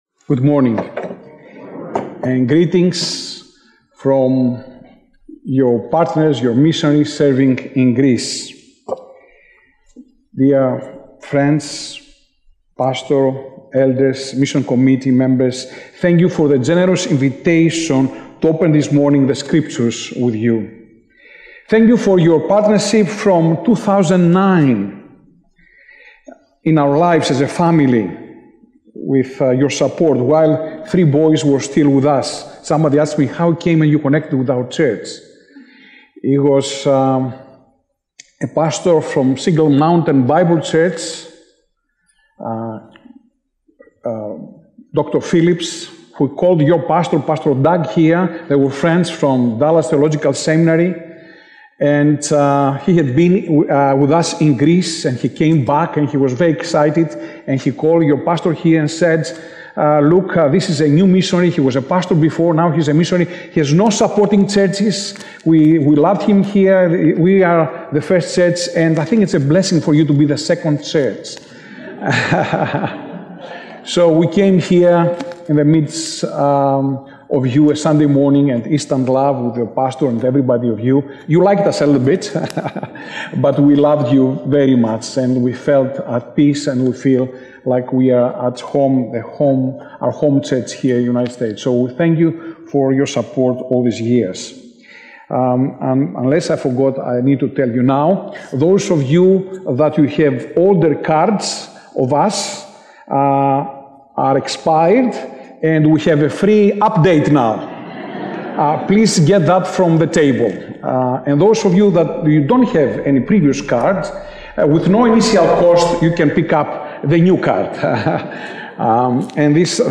Sermon Detail